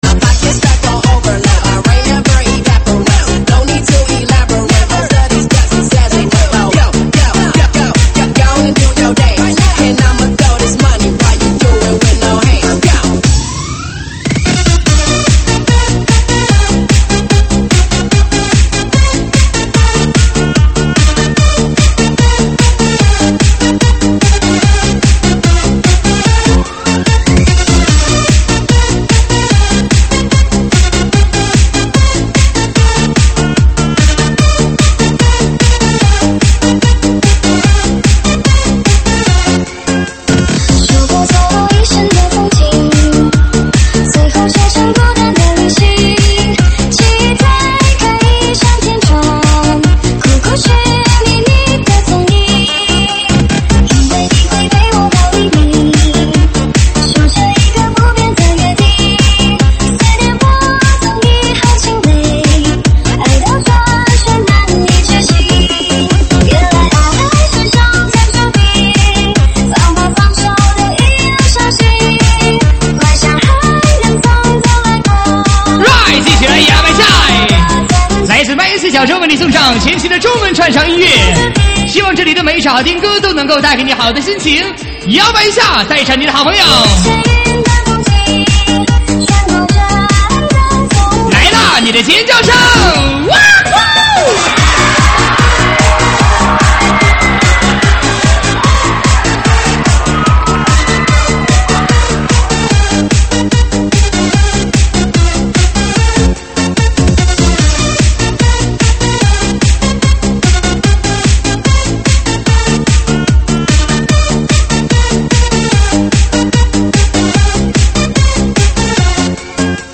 喊麦现场